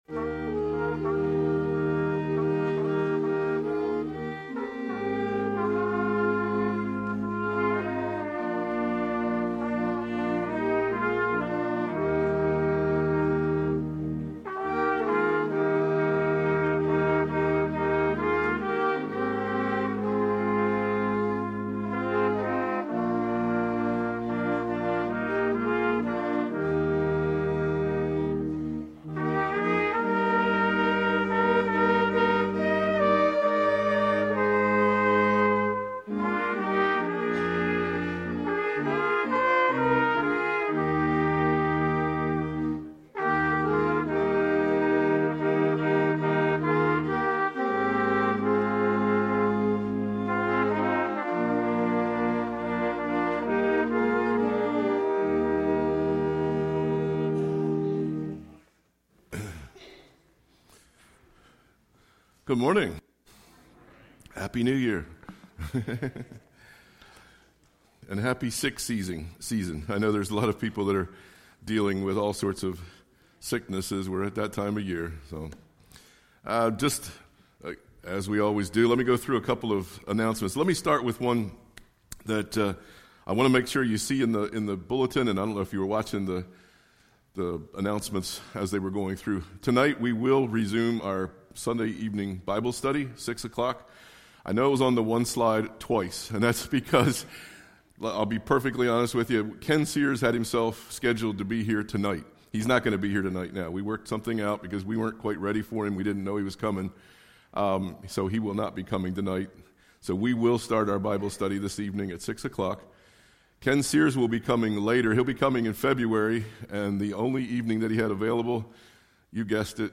Sermons by Palmyra First EC Church